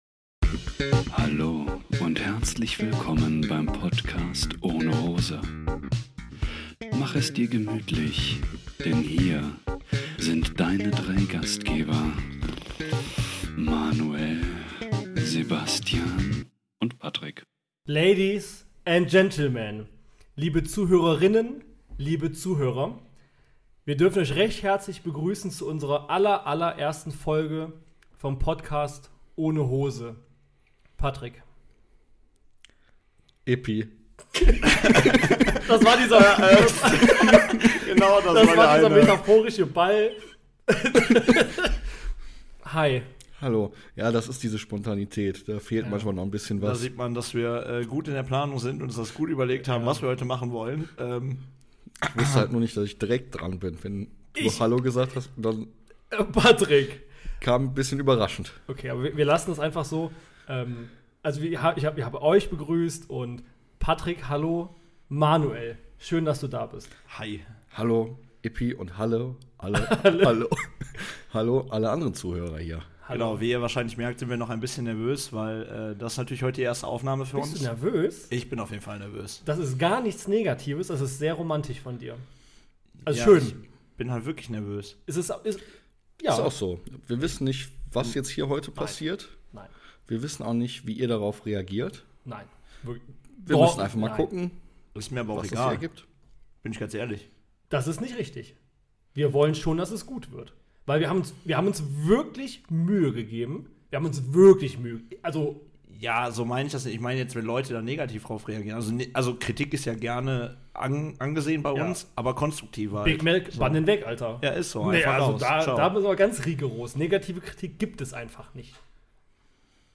In der ersten Folge geht es nicht nur darum uns und den Podcast vorzustellen... Es werden Geheimnisse gelüftet und viel gelacht.